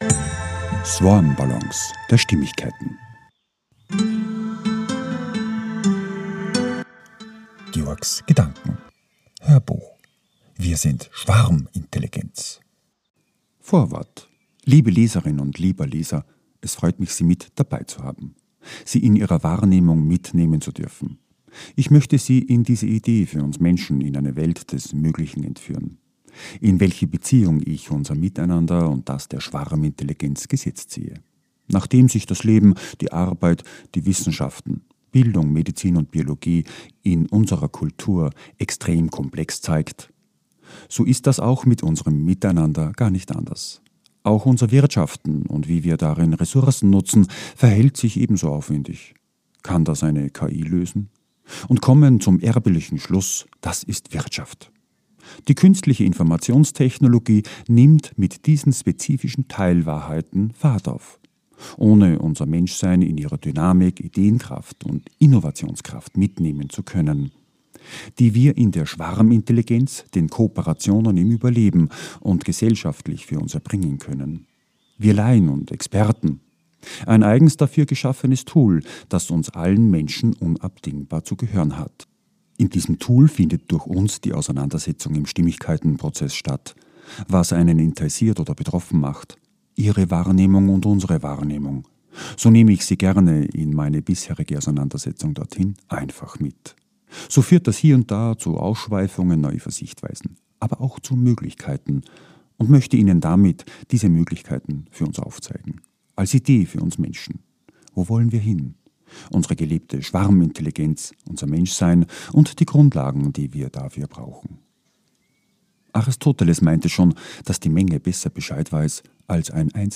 HÖRBUCH - WIR SIND SCHWARMINTELLIGENZ - VORWORT